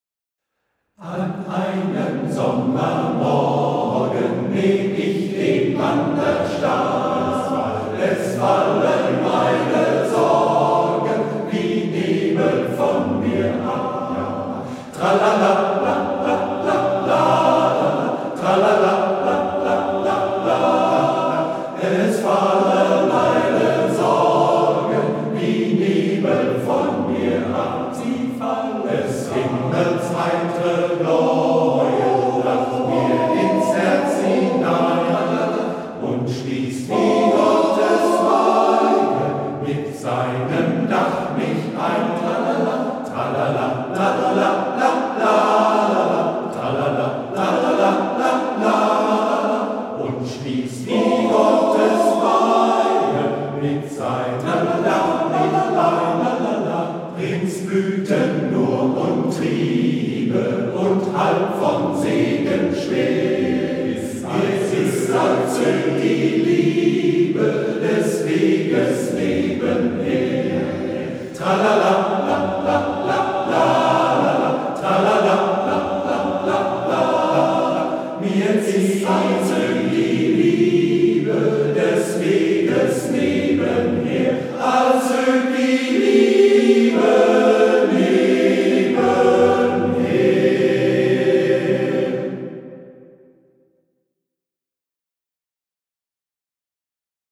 "Wanderlied" gesungen vom MGV "Cäcila" Volkringhausen e.V.